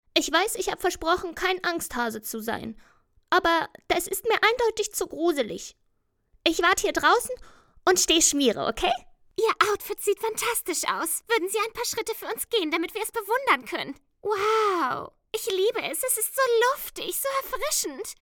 Chargen-Mix.mp3